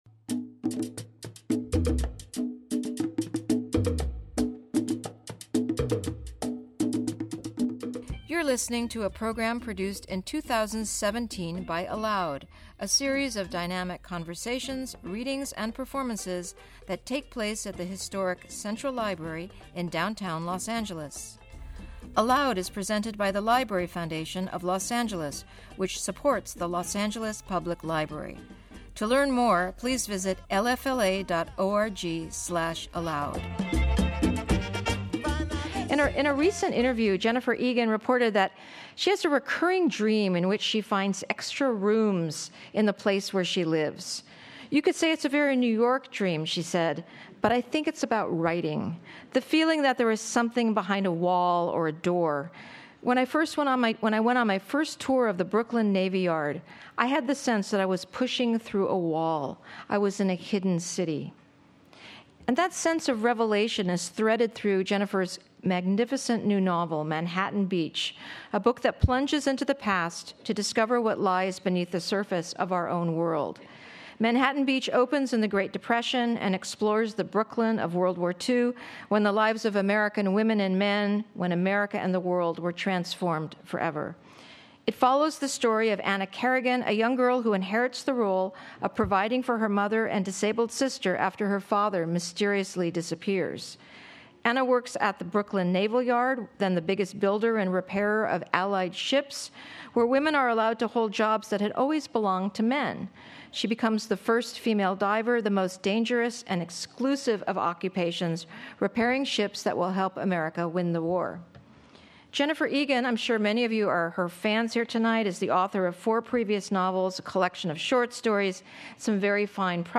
Jennifer Egan In Conversation With Marisa Silver
Sharing from this hauntingly beautiful new work, Egan takes us back to a moment in time when in the lives of women and men, America and the world transformed forever.